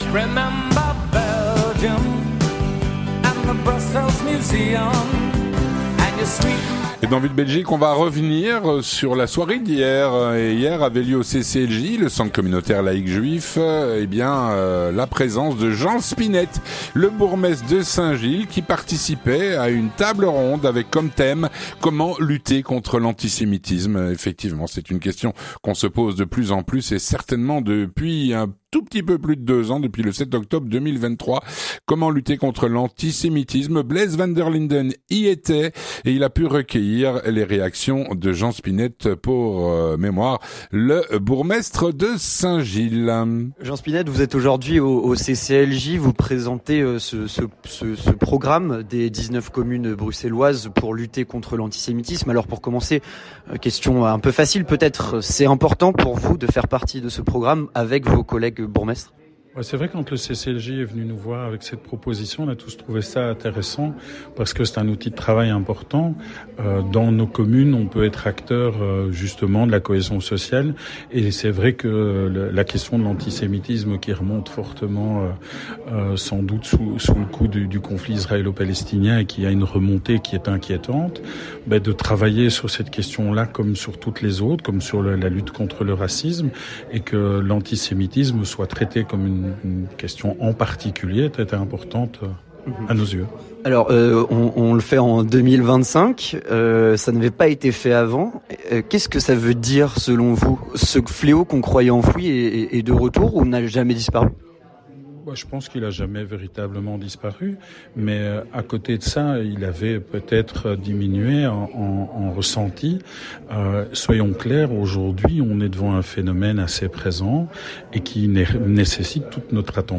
On a recueilli les réactions du bourgmestre.